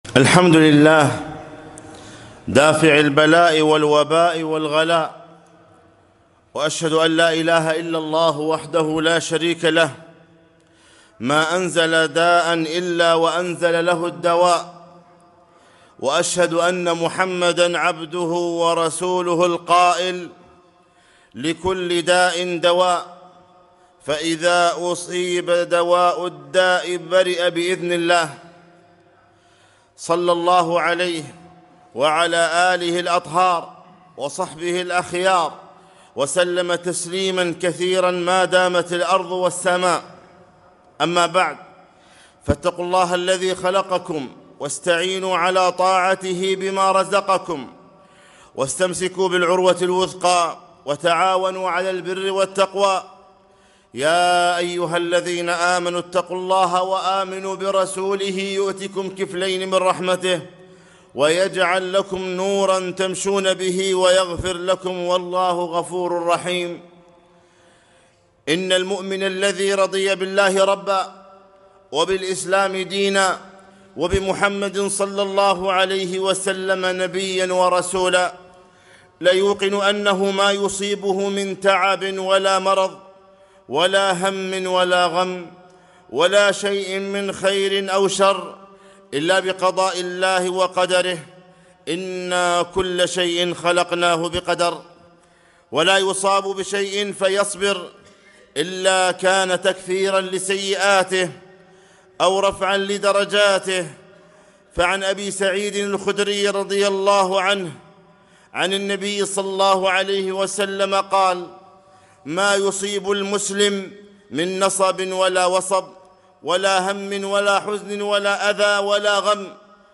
خطبة - وإذا مرضت فهو يشفين